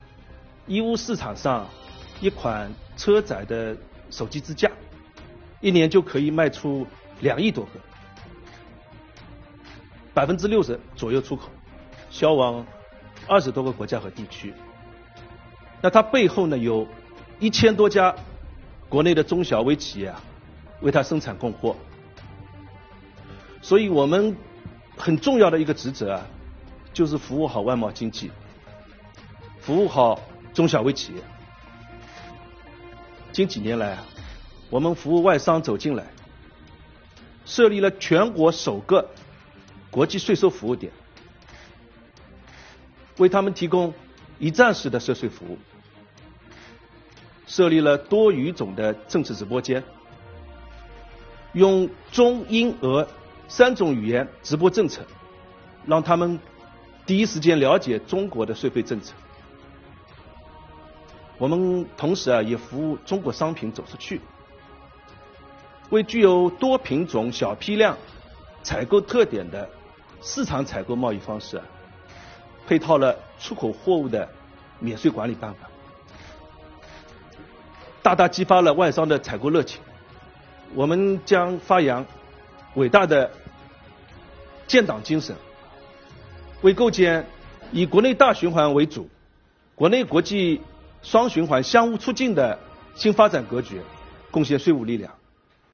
7月15日，中共中央宣传部举行中外记者见面会，邀请5名税务系统党员代表围绕“坚守初心 税收为民”主题与中外记者见面交流。